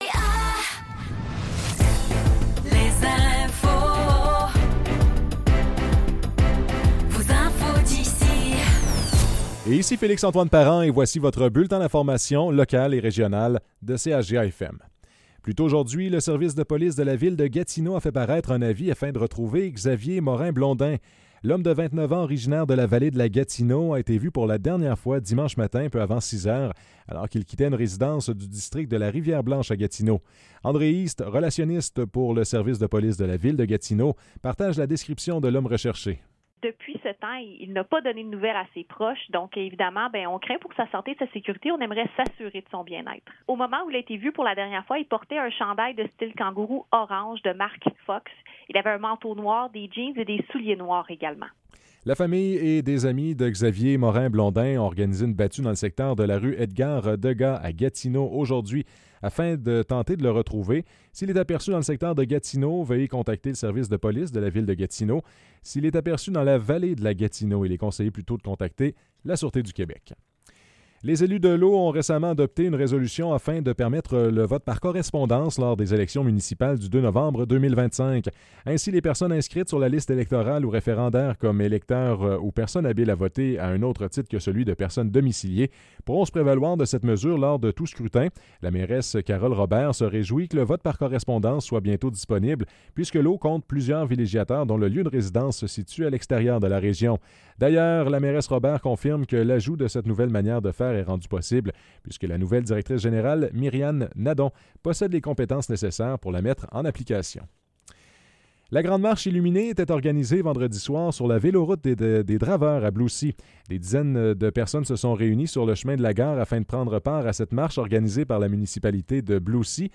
Nouvelles locales - 21 octobre 2024 - 15 h